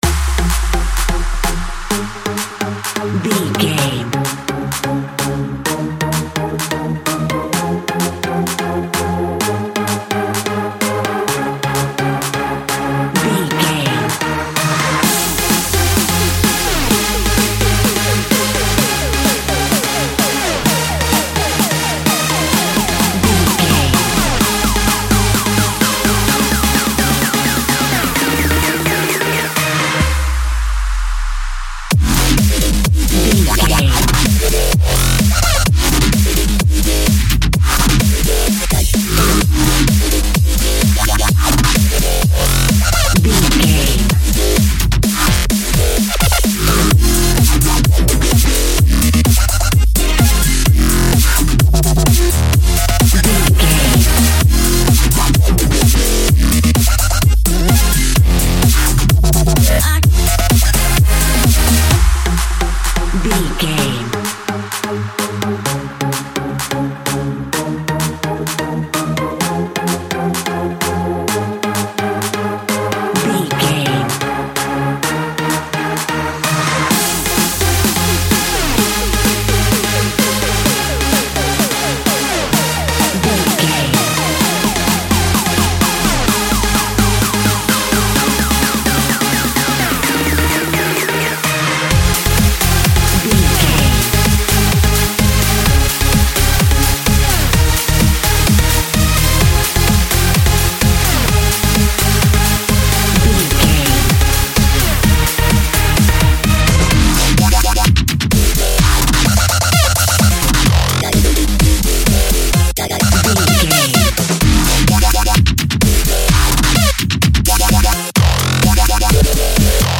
Electro Dub Dirt.
Epic / Action
Fast paced
Ionian/Major
A♭
aggressive
dark
driving
energetic
groovy
drum machine
synthesiser
dubstep
breakbeat
synth leads
synth bass